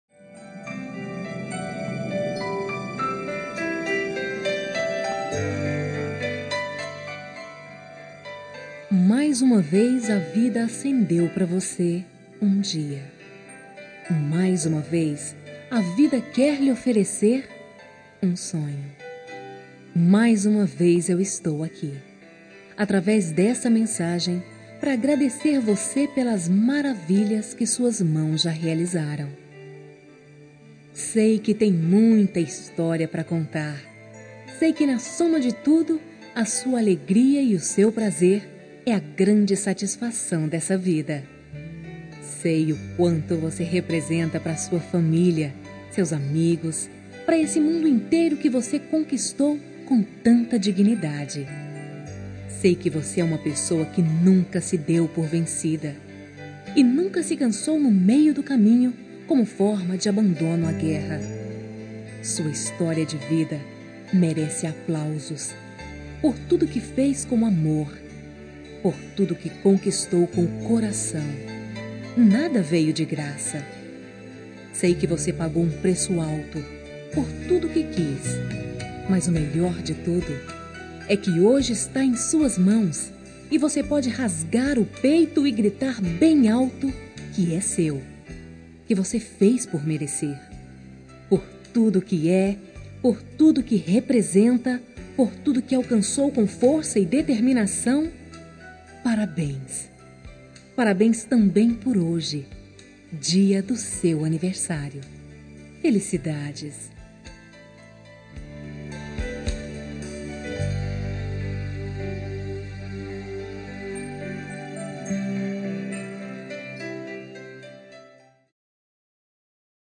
Telemensagem Aniversário de Avó – Voz Feminina – Cód: 2060 – Pessoa já vivida